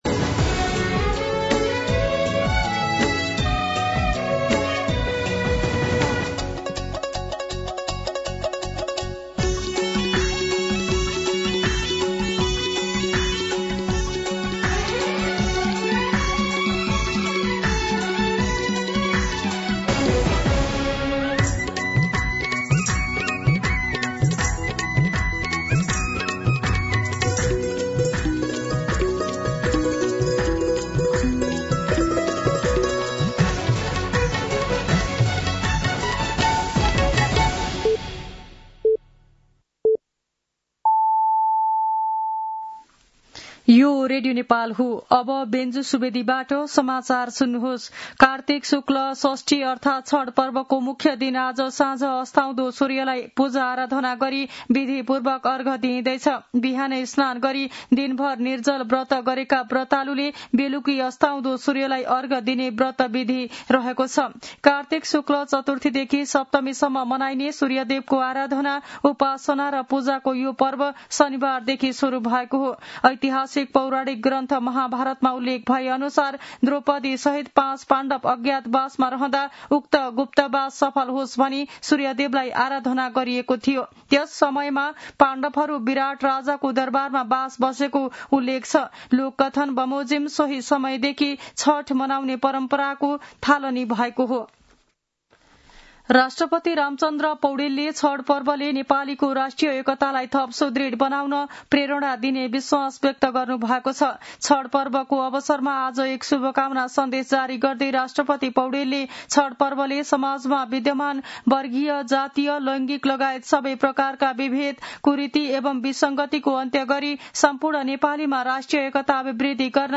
मध्यान्ह १२ बजेको नेपाली समाचार : १० कार्तिक , २०८२
12-pm-Nepali-News-10.mp3